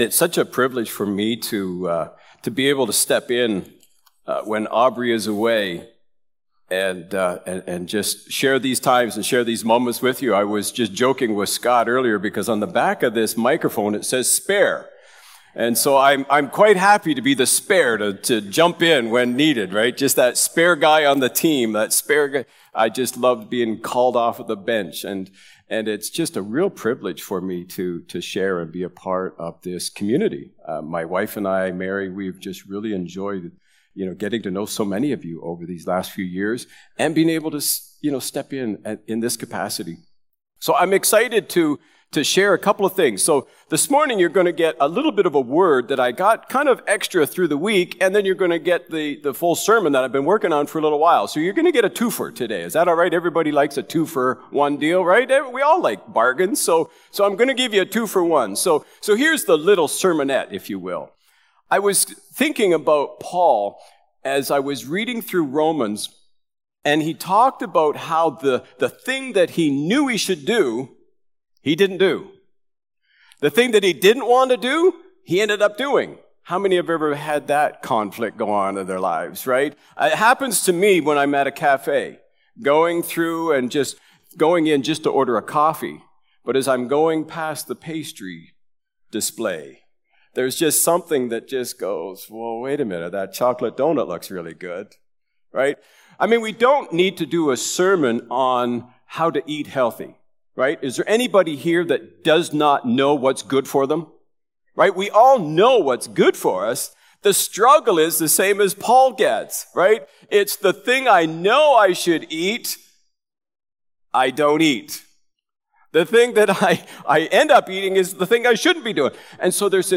July-7-Sermon.mp3